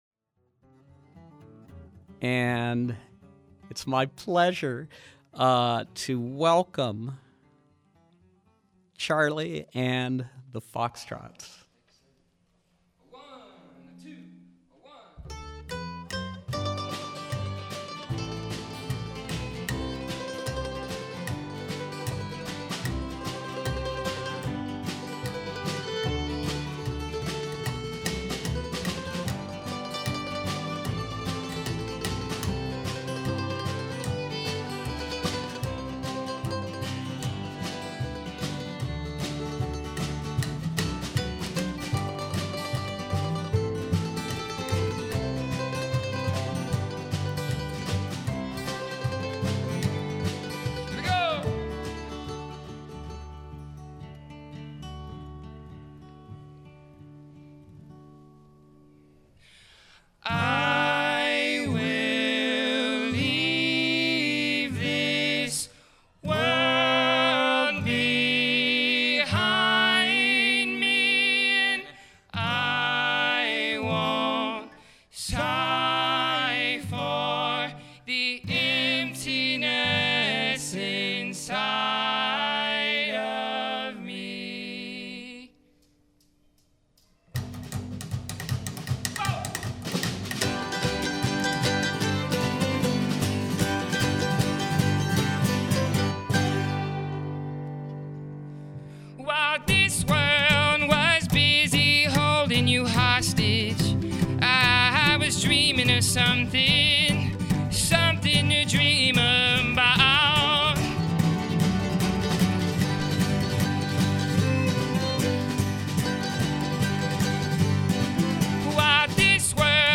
percussive acoustic folk group